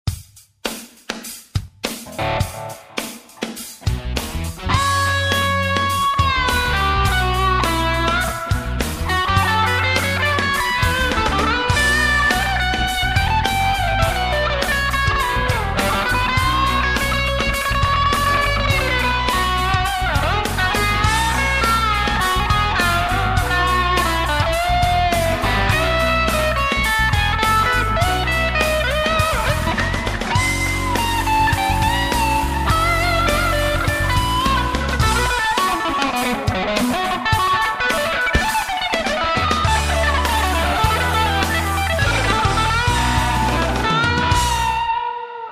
Jam tracks -- nahrajte si vlastne gitarove stopy a zverejnite!
neviem mne aj ta druha nahravka pripada velmi zvlastna.. proste niekde to znie... no proste neviem.. take rozhadzane
no neviem nejake su tie tony neiste  Žmurk